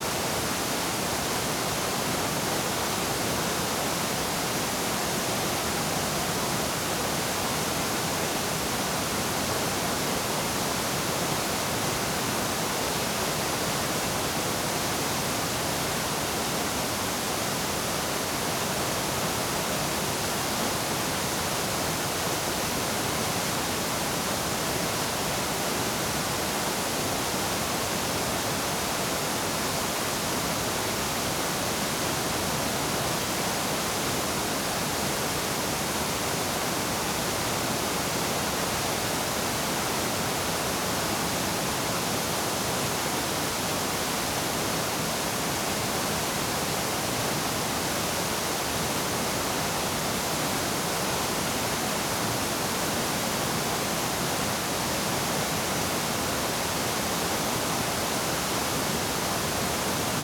waterfalls